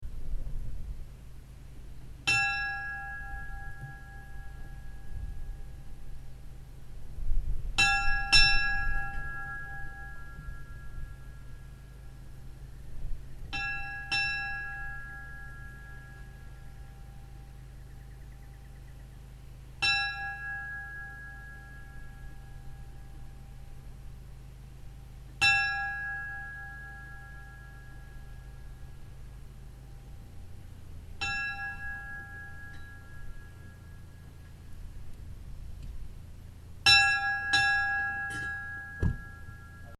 Í turni kirkjunnar eru tvær klukkur, báðar með áletruninni ANNO 1742. Klukkurnar eru ekki hátt í turninum eins og algengt er heldur eru þær í litlu herbergi í turninum ofan við inngang kirkjunnar.
Útfarir: Líkhringing er hringd á aðra klukkuna með u.þ.b. 5 sekúndna millibili.
garpsdalskirkja_likhringing.mp3